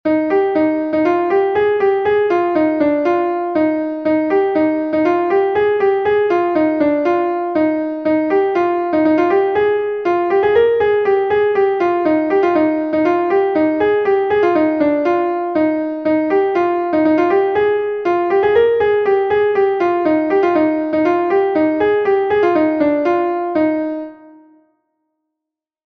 Gavotenn Bubri is a Gavotte from Brittany